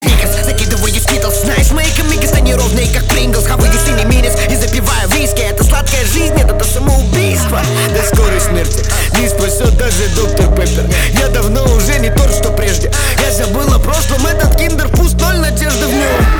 • Качество: 320, Stereo
Хип-хоп
качающие
Bass
речитатив